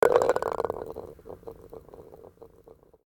Golf_Ball_Rest_In_Cup.ogg